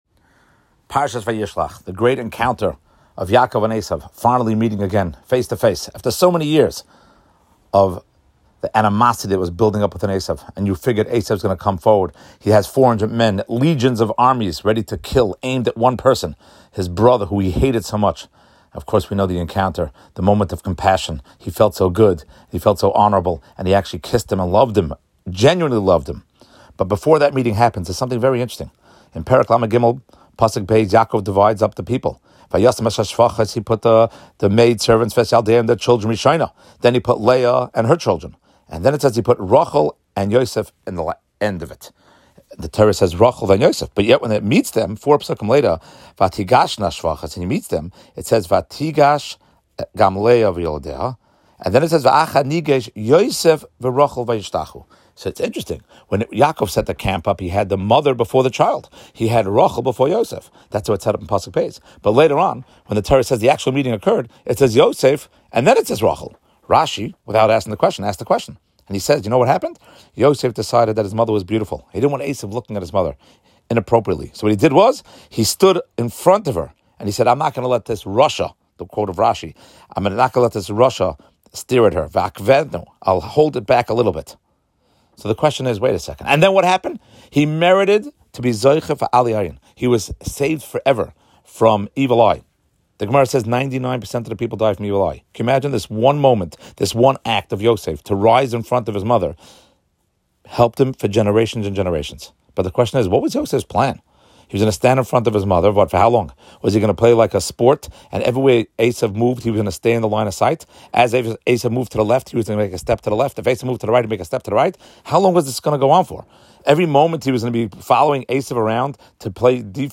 How long did he block the view? What is the secret for his great reward? Short Shiur